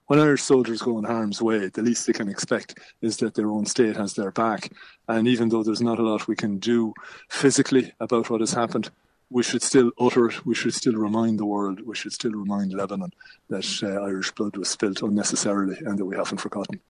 ecurity and Defence Analyst